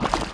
Block Tar Slidedown Sound Effect
block-tar-slidedown.mp3